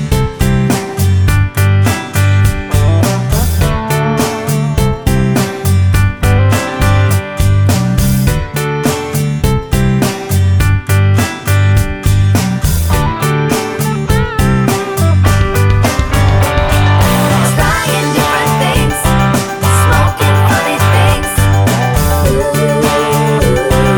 Minus Lead Guitar Pop (2010s) 4:44 Buy £1.50